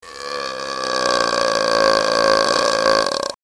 ruelpsen8.mp3